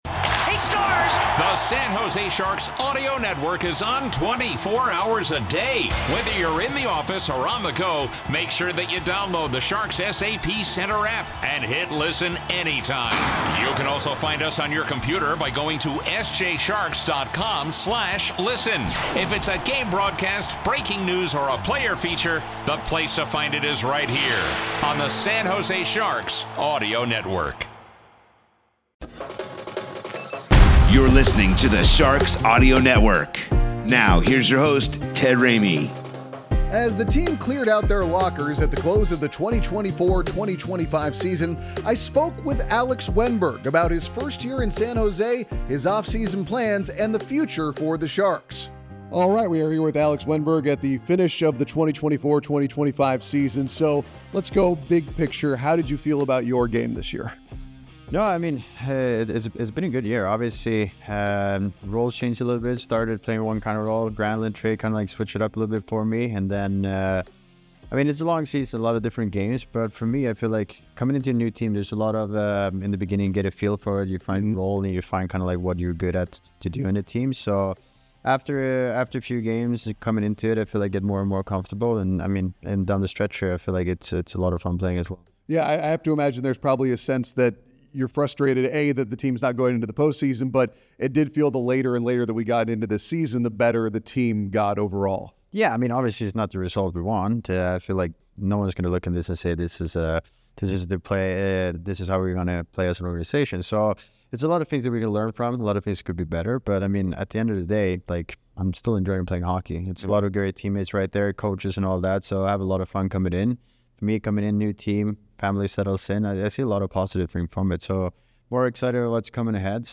Alex Wennberg Interview - End of Season Interview by Sharks Audio Network On Demand